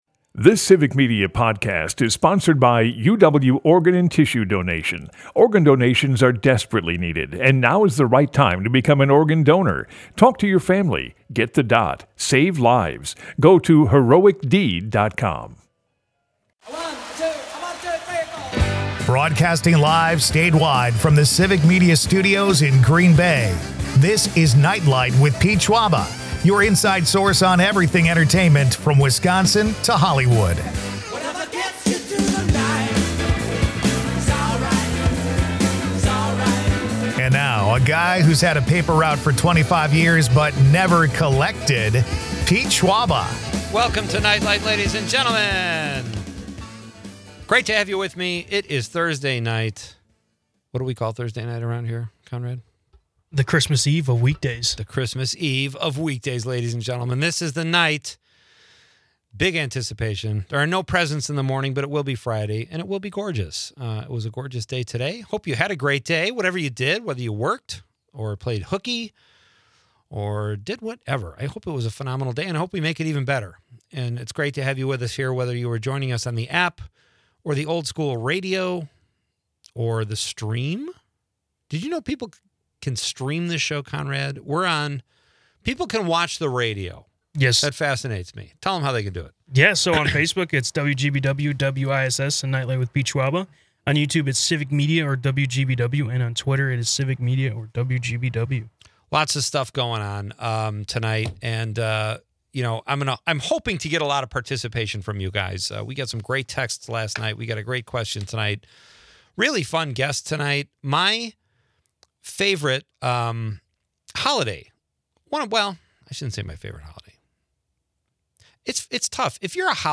Then for the Popcorn Pick of the Week, Comedian and Actor, Rick Overton joins the show. Rick shares what types of movies and tv he enjoys, what snacks are the best while binging, and then Rick recommends a movie.